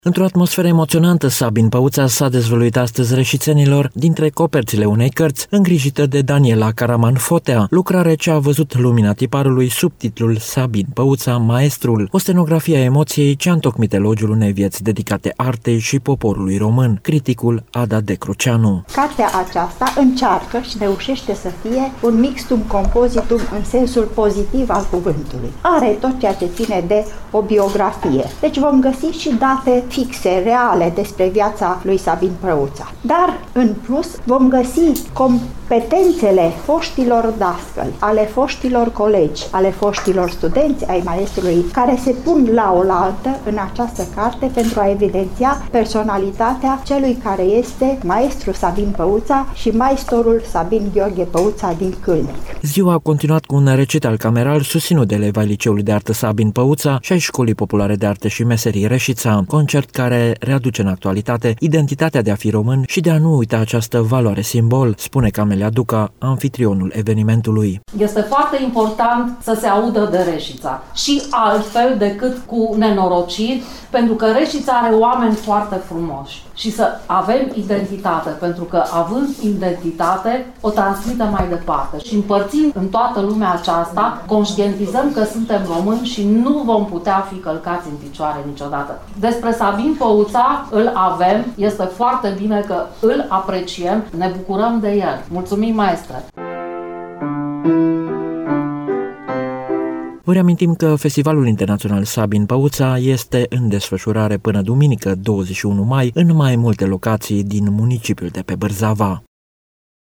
Evenimentul a avut loc  la  Biblioteca Germană  “Alexander Tietz” din Reșița